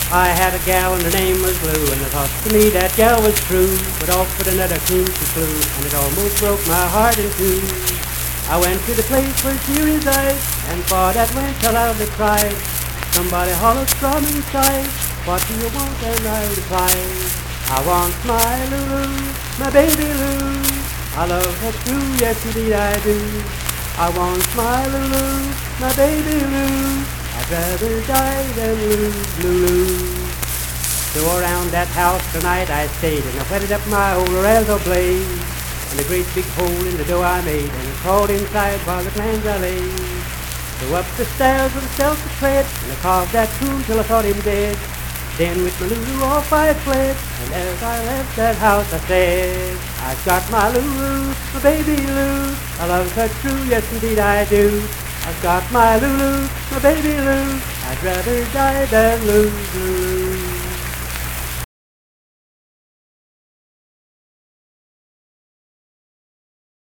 Unaccompanied vocal music
Voice (sung)
Richwood (W. Va.), Nicholas County (W. Va.)